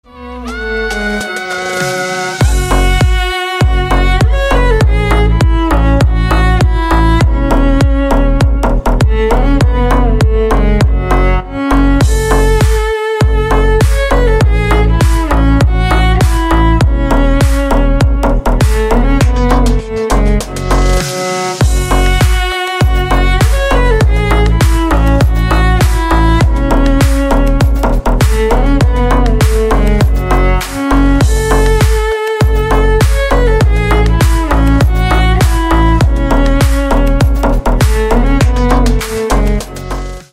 Громкие Рингтоны С Басами » # Рингтоны Без Слов
Танцевальные Рингтоны